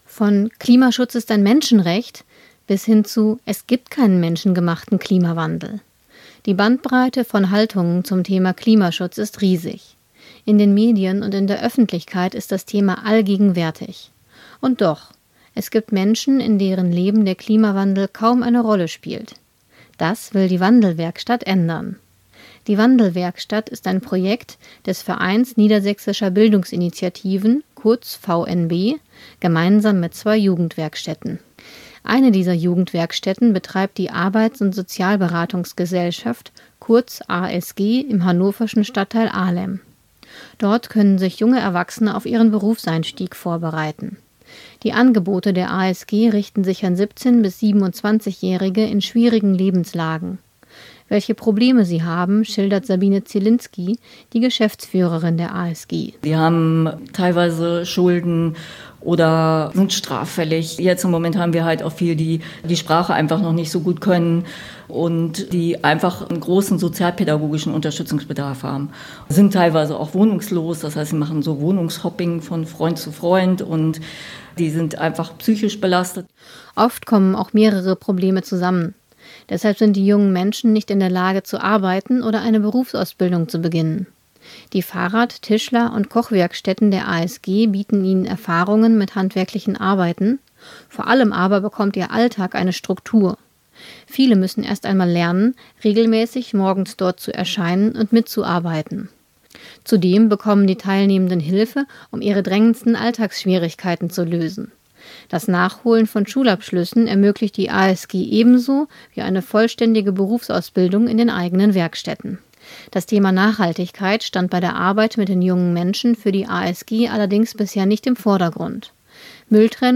Audiobeitrag über das von der Klosterkammer geförderte Projekt „WandelWerkstatt" – Nachhaltigkeitsbildung in Jugendwerkstätten